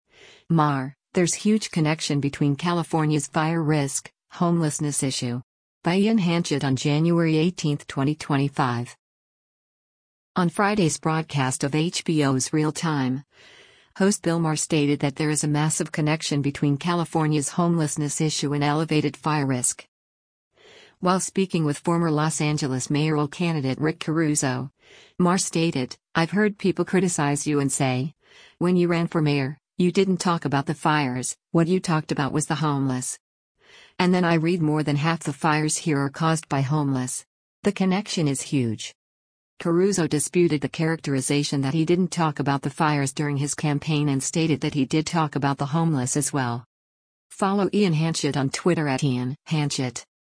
On Friday’s broadcast of HBO’s “Real Time,” host Bill Maher stated that there is a massive connection between California’s homelessness issue and elevated fire risk.